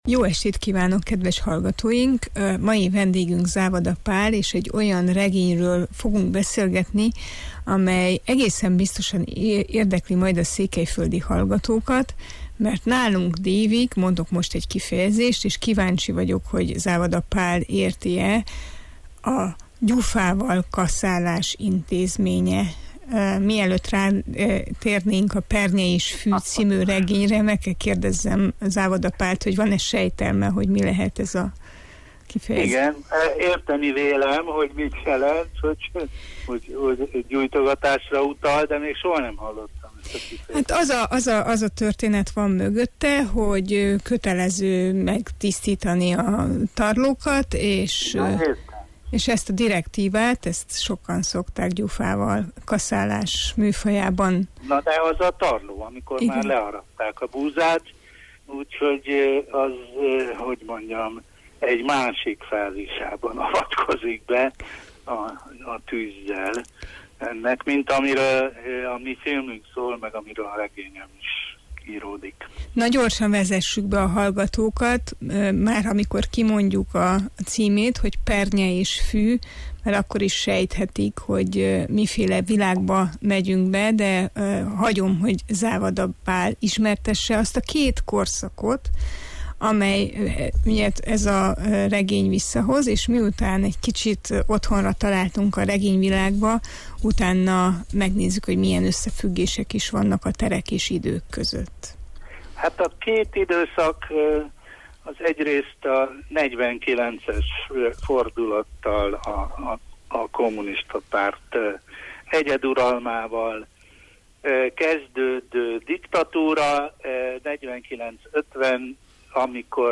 Závada Pállal, a Pernye és fű című regény szerzőjével beszélgettünk.